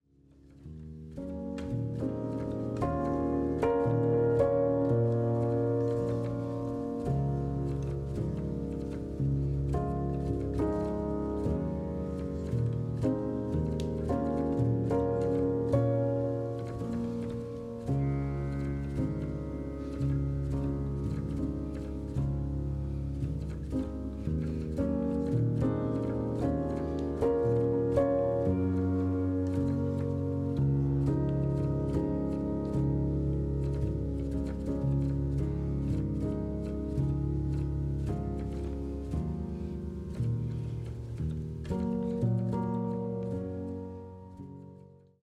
ポスト・クラシカル
一歩引いたところから自分を、世界を見る、静かな熱量。